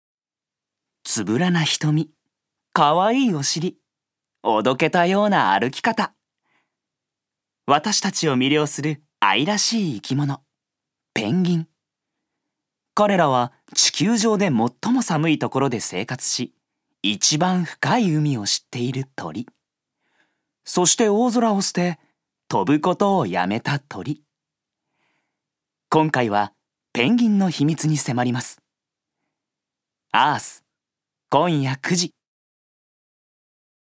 所属：男性タレント
音声サンプル
ナレーション２